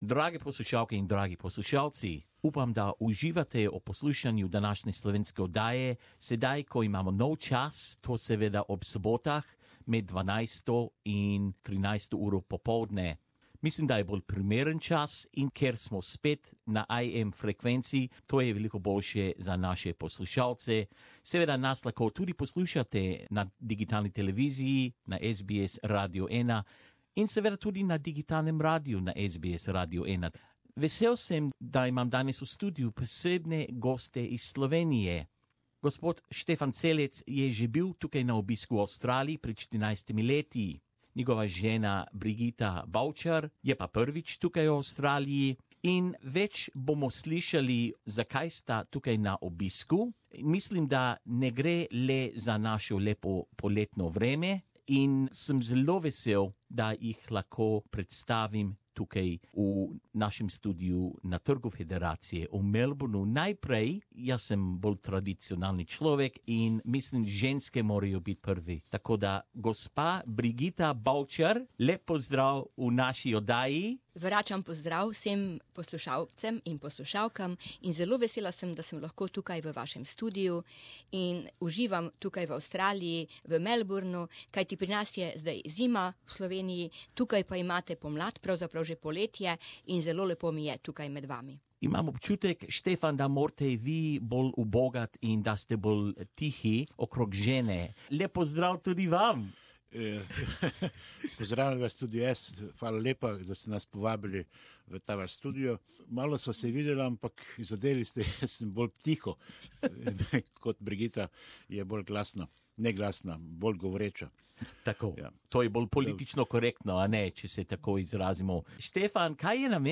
They join us for a chat.